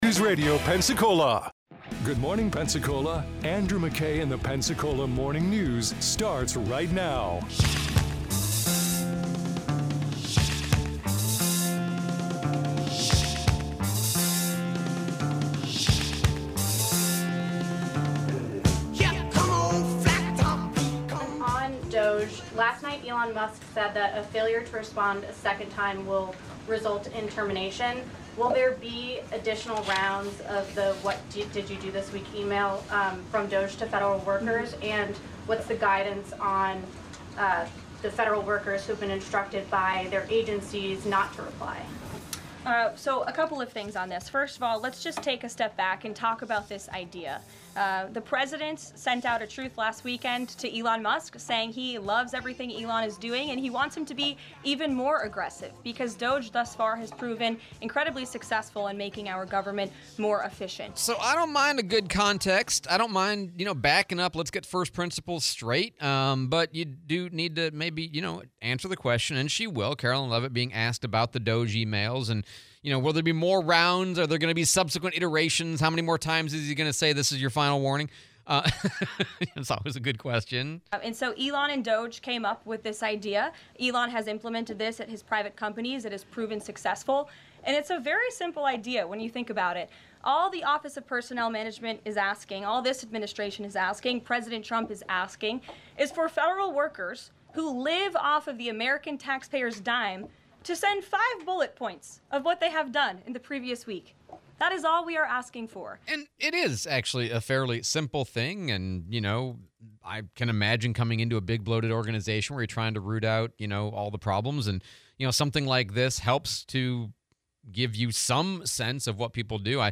DOGE press conference, interview